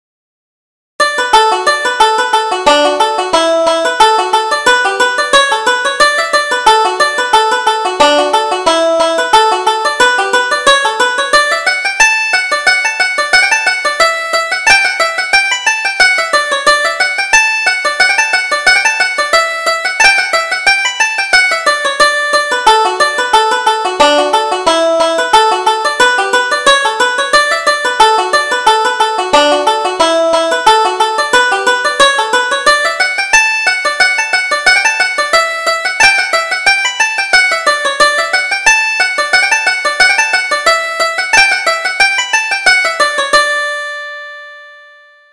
Reel: Bonnie Kate